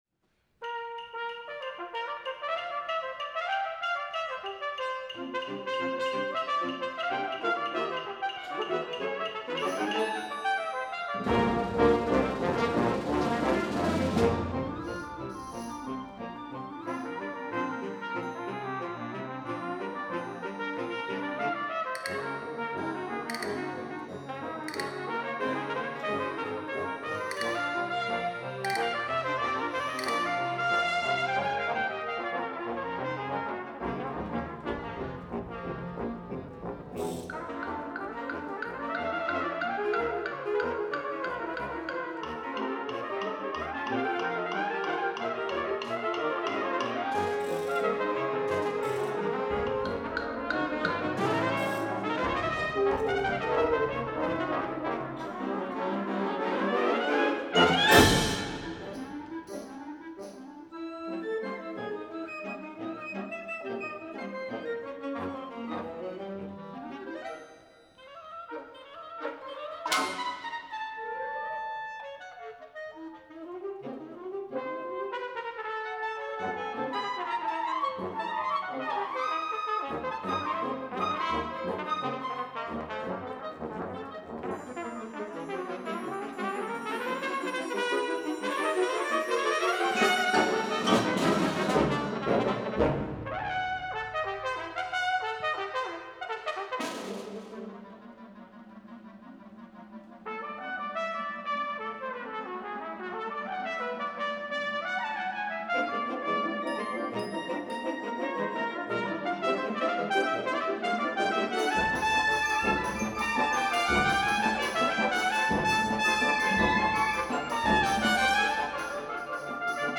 trumpet (live and fully memorized